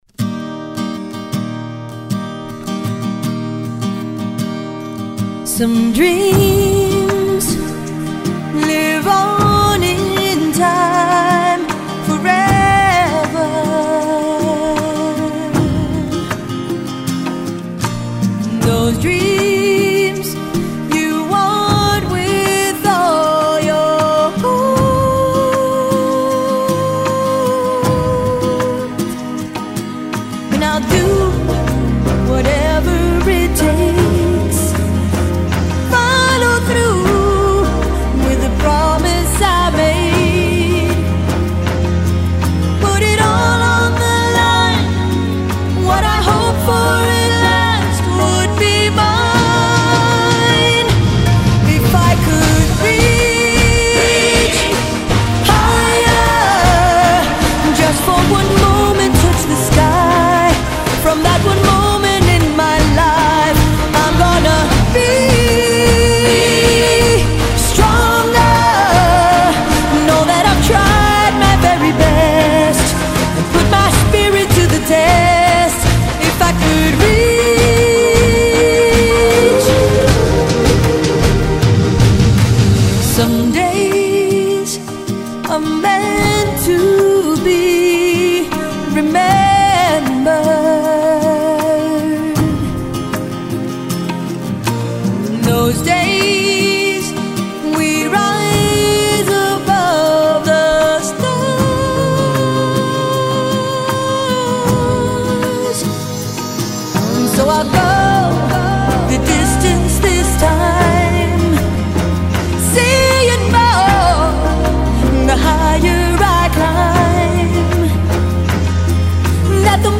Music Soundtrack – Latin/Pop Flava “Double-Play”